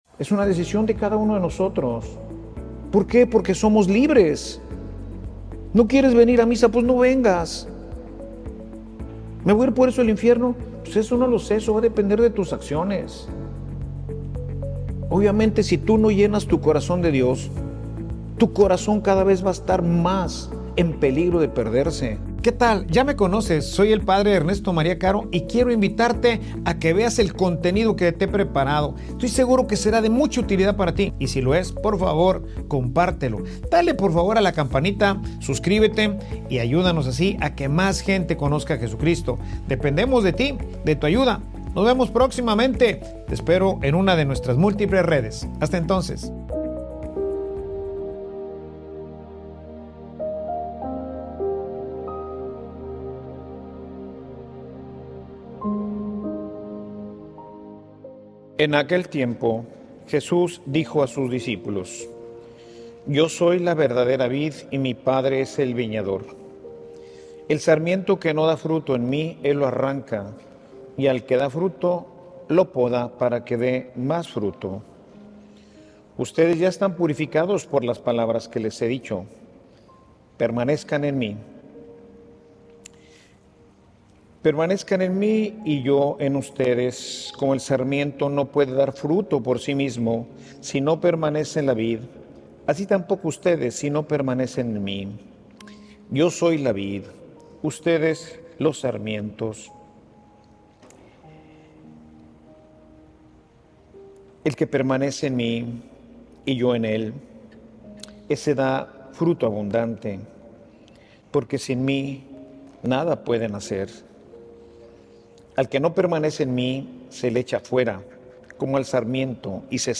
Homilia_Sufrimos_por_rechazar_a_Dios.mp3